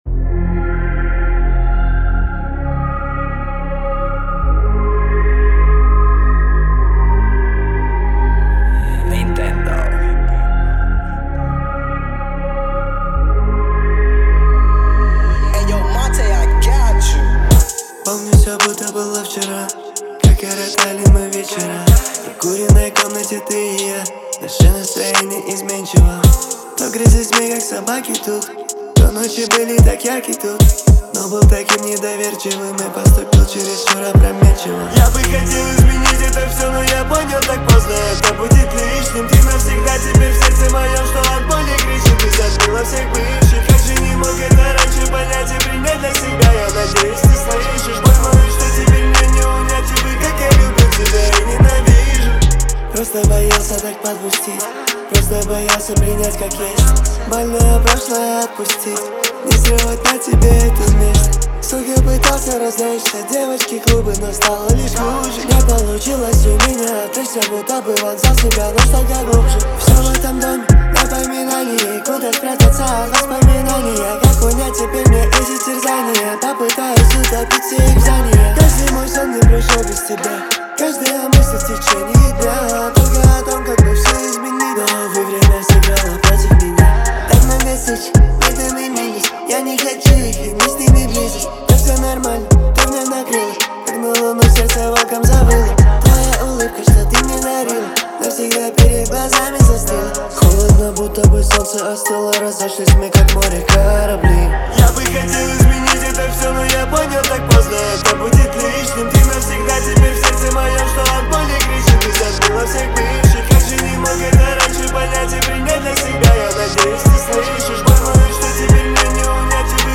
сочетая рэп и мелодичный вокал.